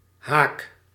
Ääntäminen
IPA: /ɦa:k/